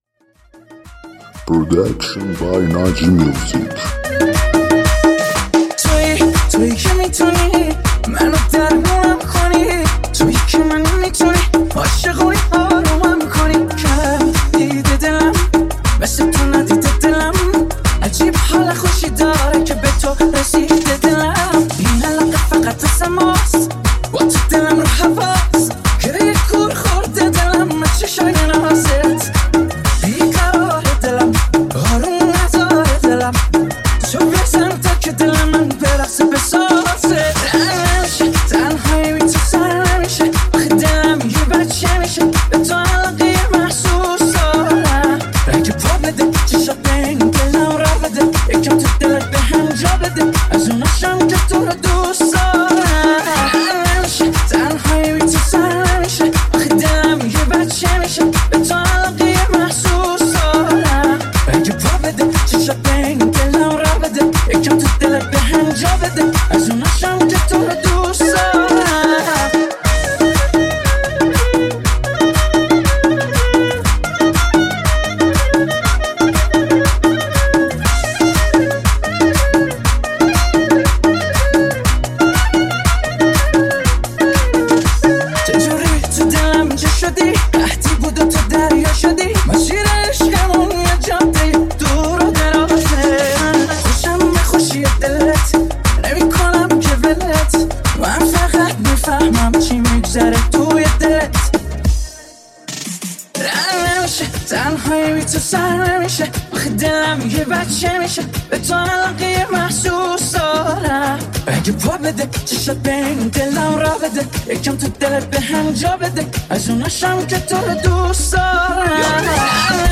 دانلود ریمیکس شاد تریبال مخصوص رقص